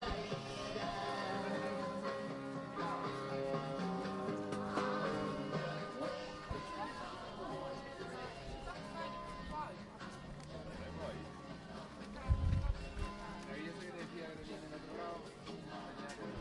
繁忙的市场
描述：在变焦h5上使用2 x AKG 414进行现场录制。
标签： 市场 街道 谈话 拥挤 人群中 现场记录
声道立体声